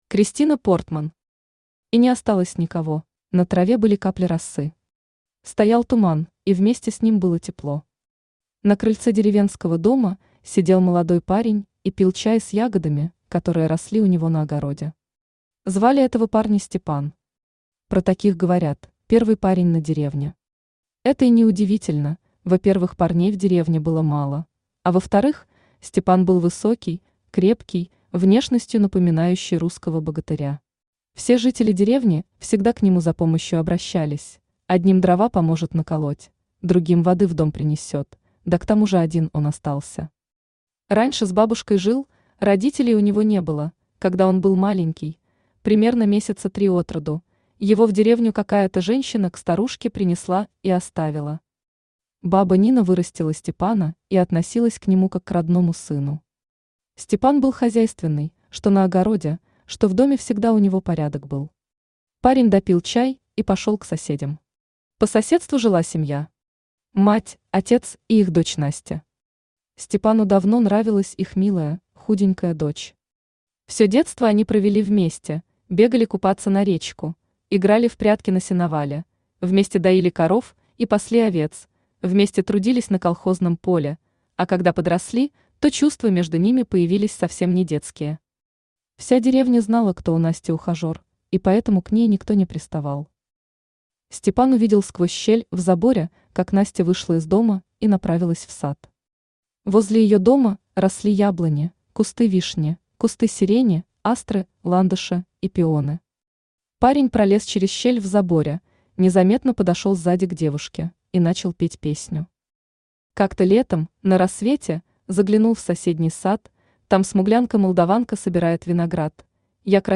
Аудиокнига И не осталось никого | Библиотека аудиокниг
Aудиокнига И не осталось никого Автор Kristina Portman Читает аудиокнигу Авточтец ЛитРес.